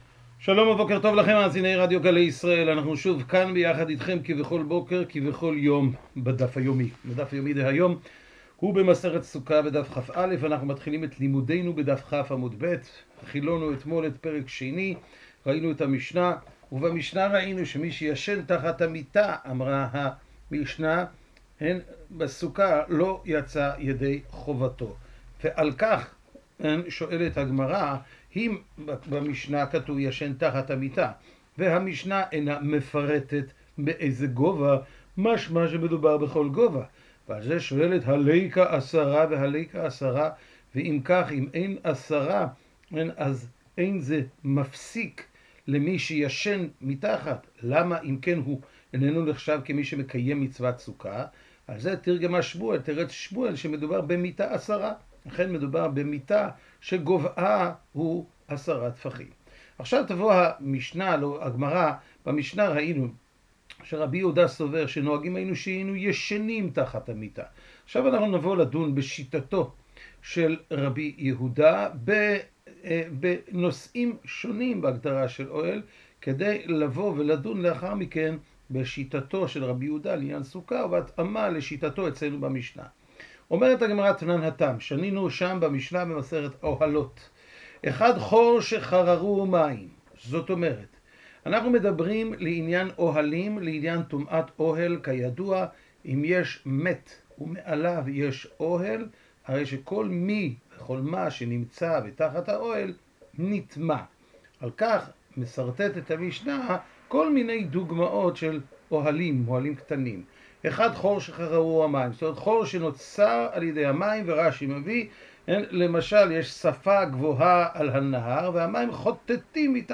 השיעור משודר בשעה 05:30 בבוקר ברדיו גלי ישראל וכל היום באתר סרוגים.